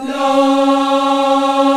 AFROLA C#4-L.wav